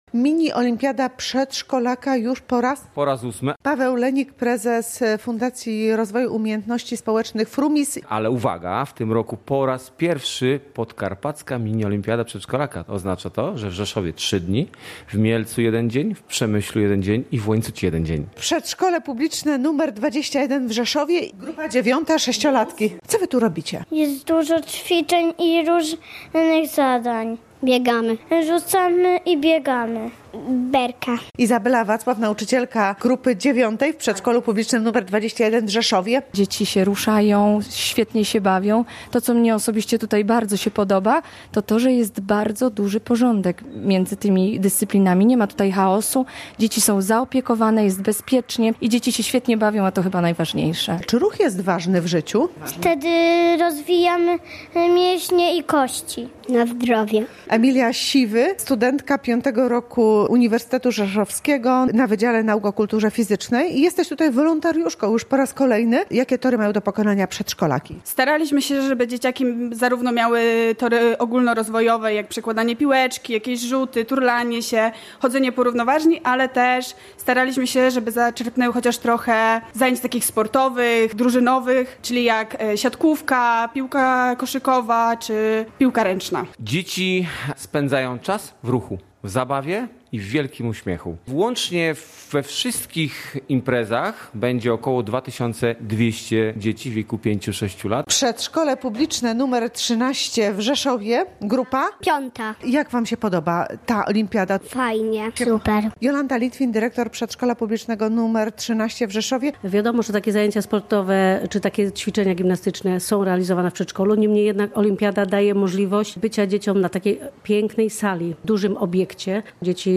Wiadomości • Ponad 2200 pięcio.- i sześciolatków weźmie udział w Pierwszej Podkarpackiej Mini Olimpiadzie Przedszkolaka.